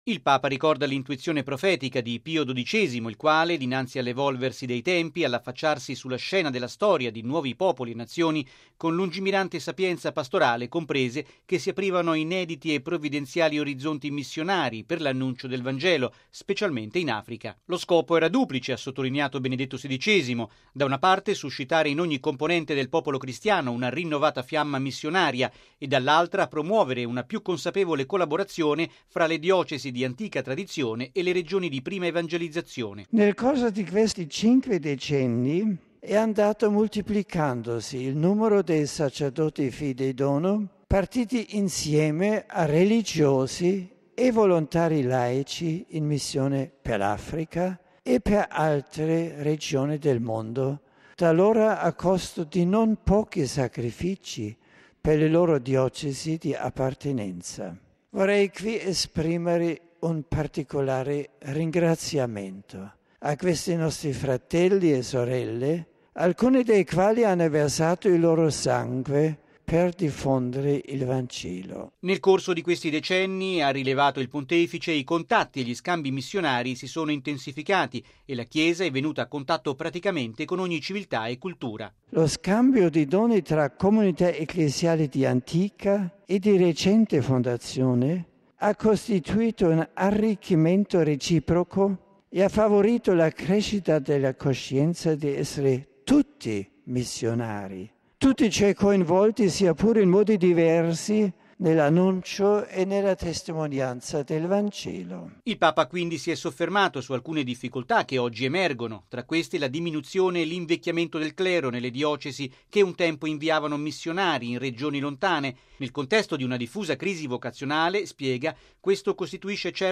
E’ quanto ha detto stamane il Papa ricevendo i partecipanti all'Incontro del Consiglio Superiore delle Pontificie Opere Missionarie e al Congresso Mondiale dei Missionari fidei donum, in occasione del 50° anniversario dell’Enciclica Fidei donum di Pio XII.